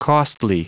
Prononciation du mot costly en anglais (fichier audio)
Prononciation du mot : costly